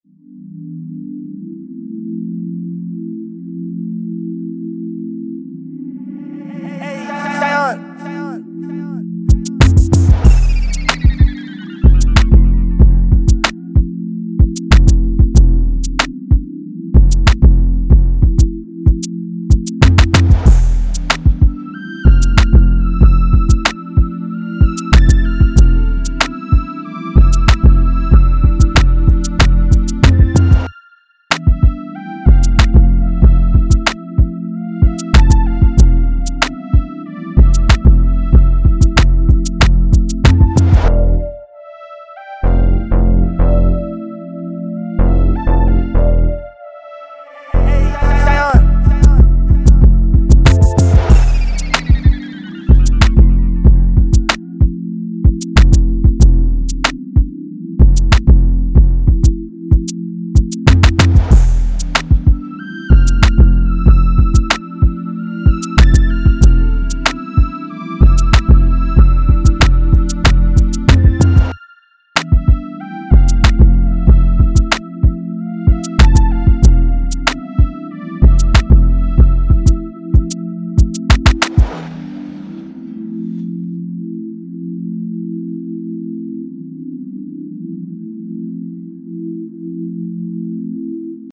94 D Minor